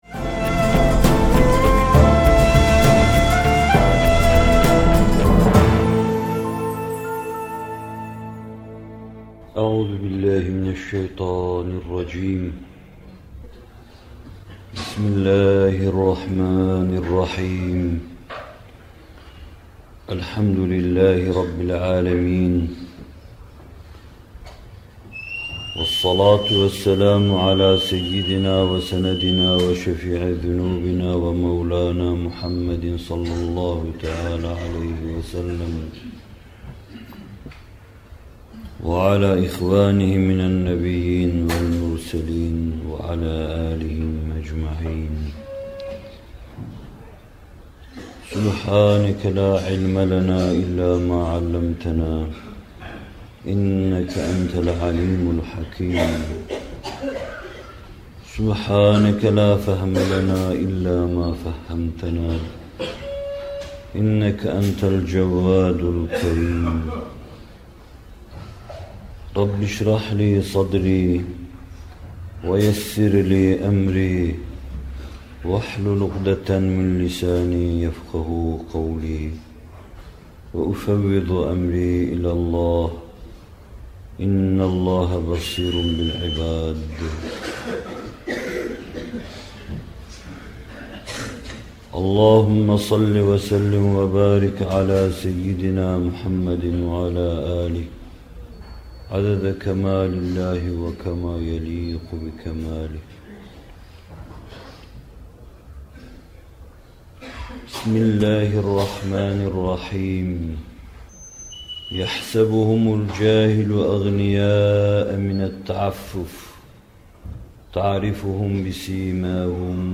Zekat Vaazları – Zenginleşme Vergiyle Değil, Allah’ın Teşrii ve Tekvini Emirlerine Uyan… (24.Bölüm) - Fethullah Gülen Hocaefendi'nin Sohbetleri